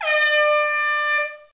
Mode Campaign Airhorn Sound Effect
Download a high-quality mode campaign airhorn sound effect.
mode-campaign-airhorn.mp3